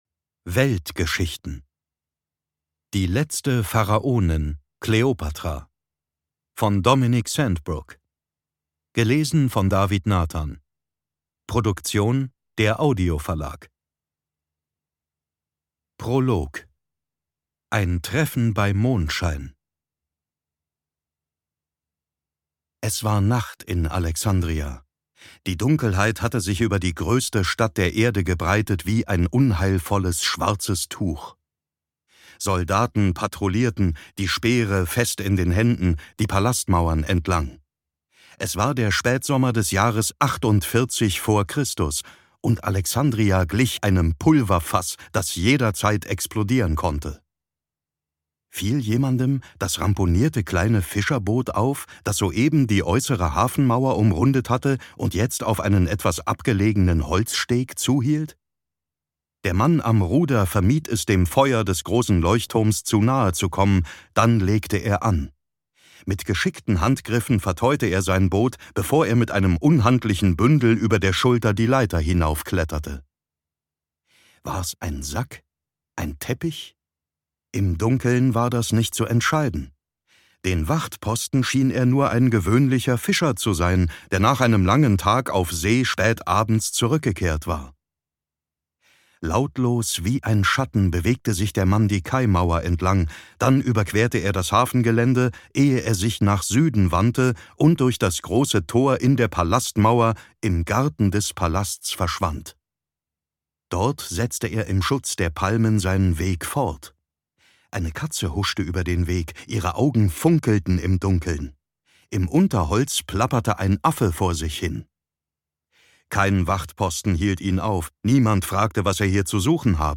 Ungekürzte Lesung mit David Nathan (1 mp3-CD)
David Nathan (Sprecher)
Seine ausdrucksstarke Stimme lieh er Johnny Depp, Christian Bale, Val Kilmer, Leonardo DiCaprio u. v. a. Für seine Hörbuchlesungen wurde er vielfach ausgezeichnet, u. a. mit dem Deutschen Hörbuchpreis und dem Preis der Deutschen Schallplattenkritik.